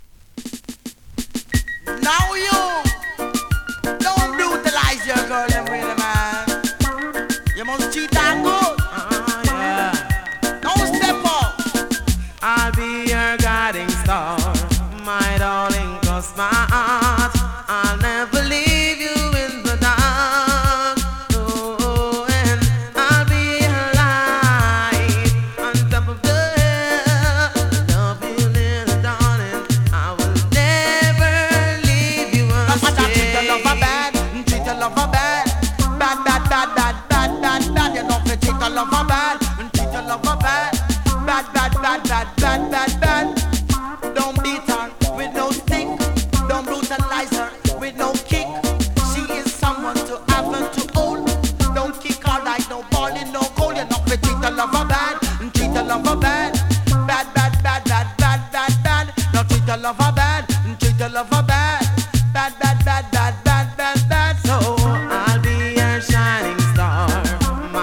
DANCEHALL!!
スリキズ、ノイズかなり少なめの